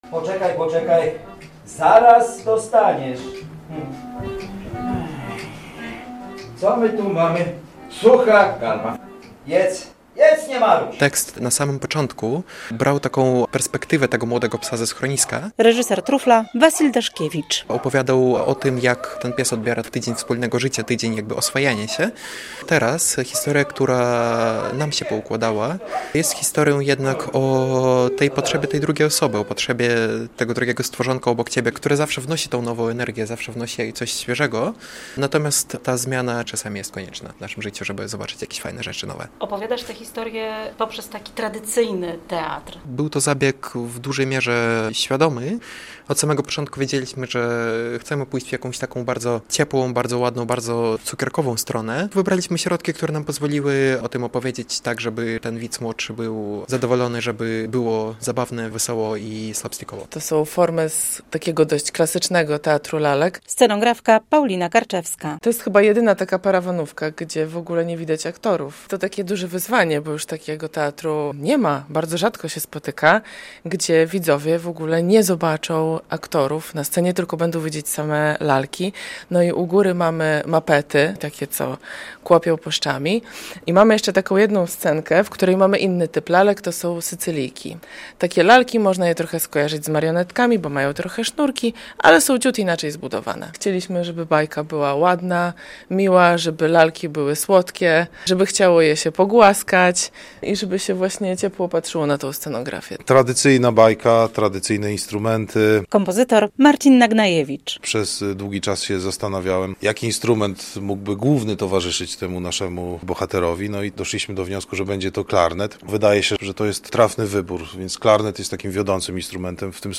"Trufel", premiera w BTL- relacja